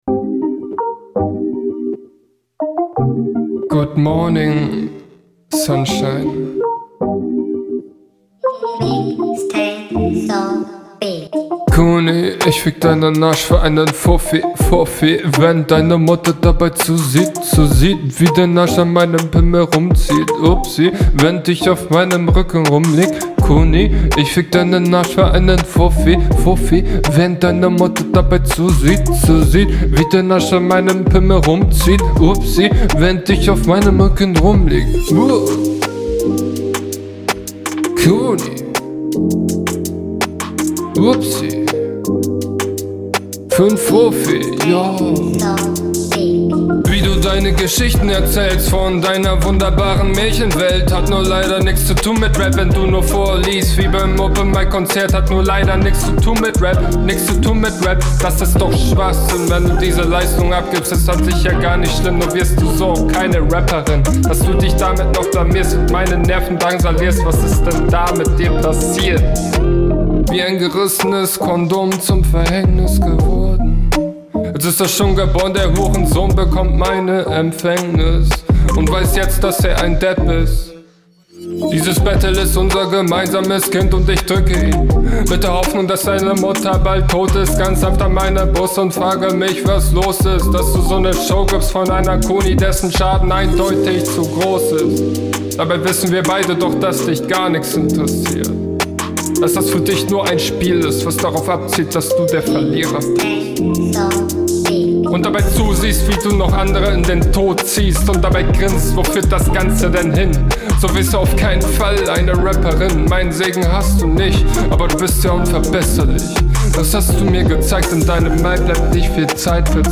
Beat is ok, hook hat aber irgendwas weirdes ansich haha.